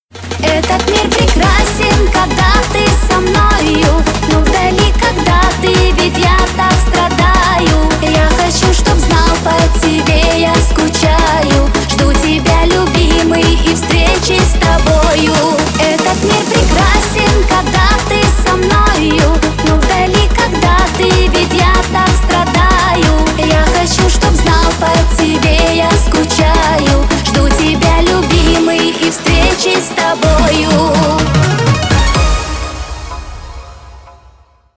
Нарезка припева на вызов